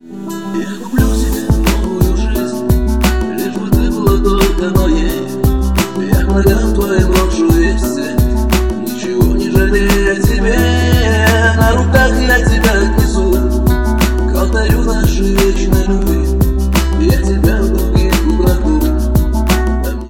романтические